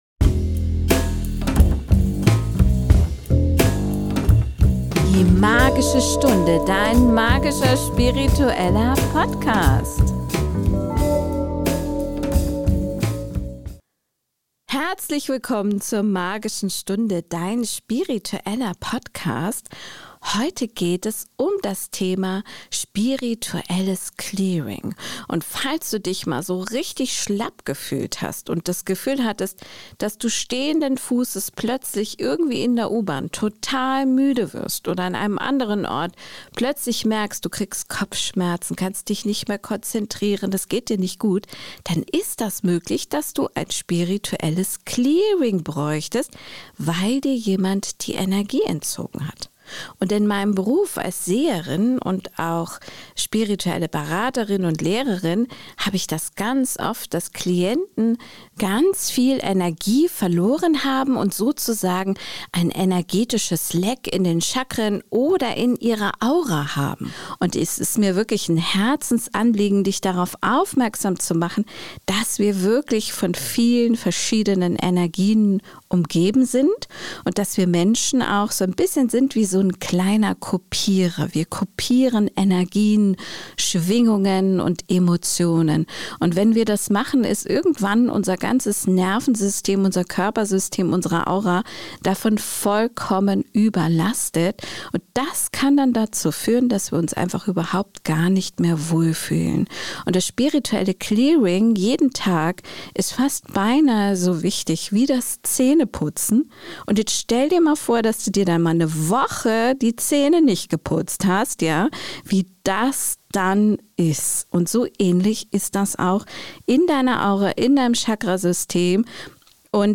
Ich führe dich durch bewährte Methoden wie Chakra-Arbeit, Räuchern und Pflanzenwasser und zeige dir, wie du dein Wurzelchakra reinigst. Am Ende der Episode lade ich dich zu einer geführten Meditation ein, die dich sofort energetisch klärt.